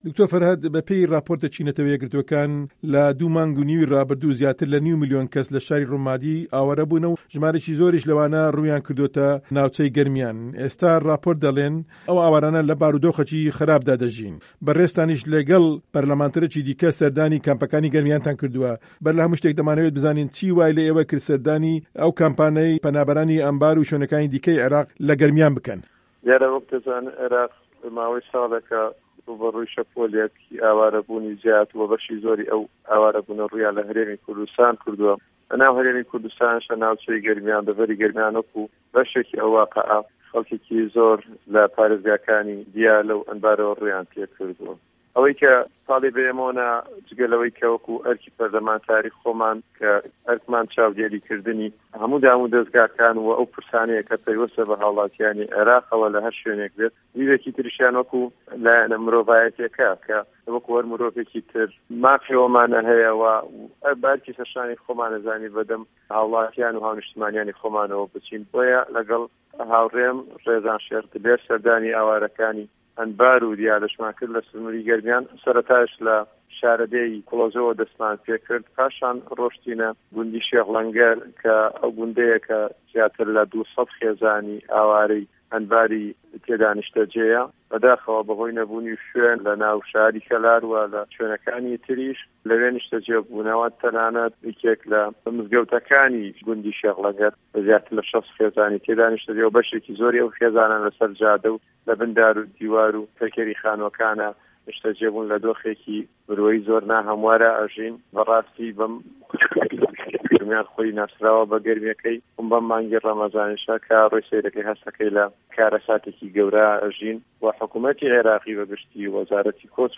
دکتۆر فەرهاد قادر ئەندامی پەرلەمانی عێراق لەسەر لیستی یەکێتی نیشـتیمانی کوردسـتان کە سەردانی کەمپەکانی پەنابەرانی لە گەرمیانی کردووە لە وتووێژێـکی تایبەتدا باس لە ڕەوشی ئاوارەکان دەکات.
وتووێژی دکتۆر فەرهاد قادر